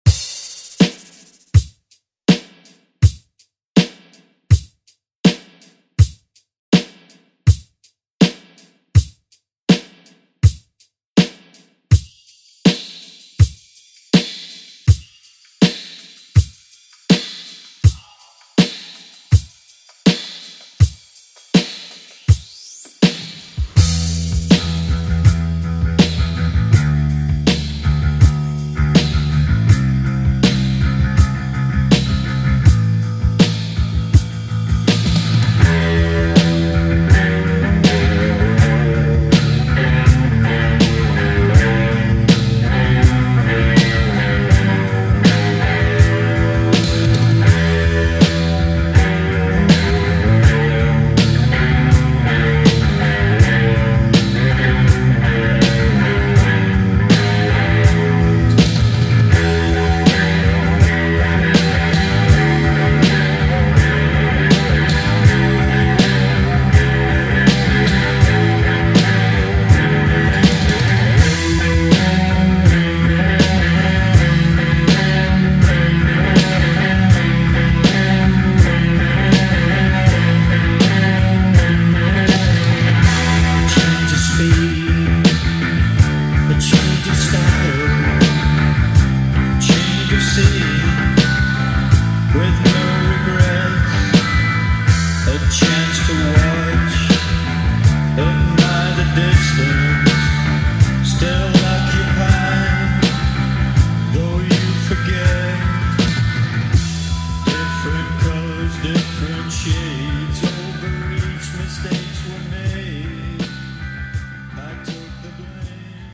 Genre: 70's Version: Clean BPM: 81 Time